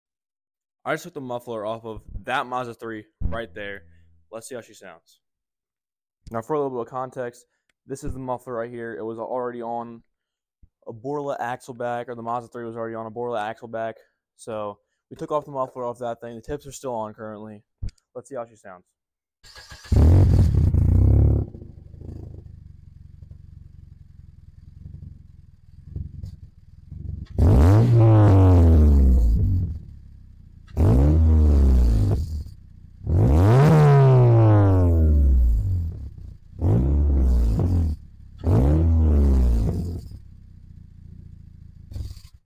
Raw no muffler Mazda 3 sound effects free download
Raw no muffler Mazda 3 revs, did have a Boris lacebark muffler on it before cutting out